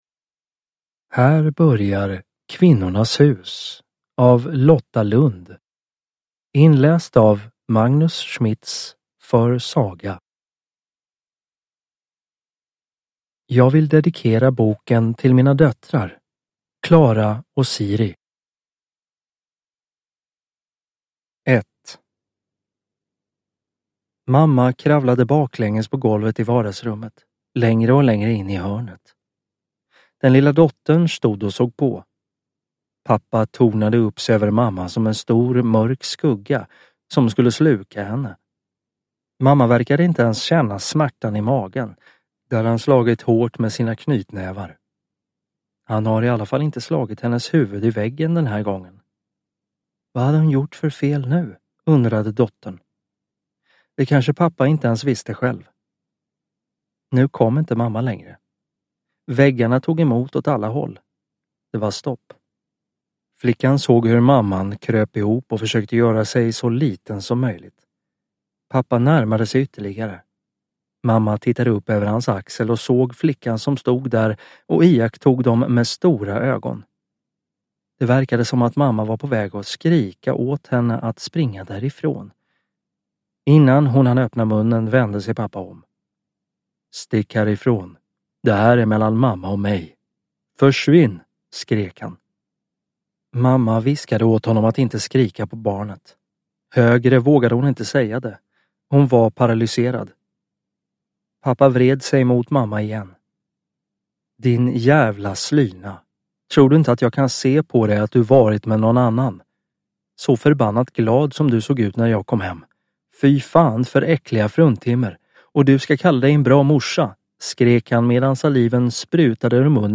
Kvinnornas hus – Ljudbok – Laddas ner